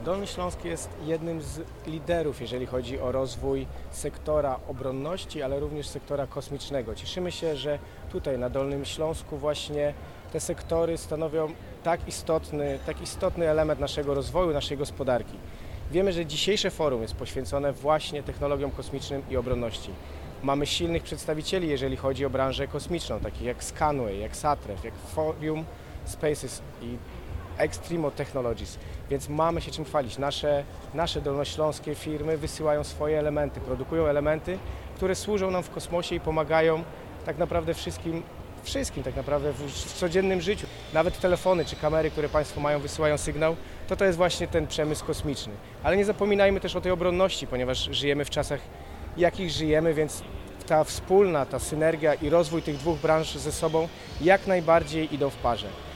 Na kilka dni przed ponownym otwarciem, na wrocławskim lotnisku zagościło Dolnośląskie Forum Innowacyjne.
O roli Dolnego Śląska w budowaniu polskich kompetencji technologicznych w kontekście sektora obronnego i kosmicznego mówił Michał Rado, wicemarszałek Województwa Dolnośląskiego.